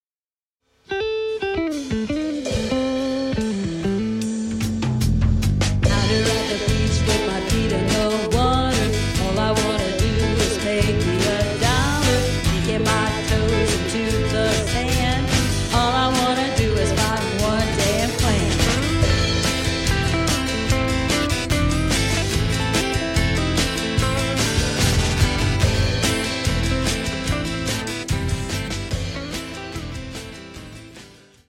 Acoustic guitar & Lead Vocals
Electric guitar
Bass guitar
Drums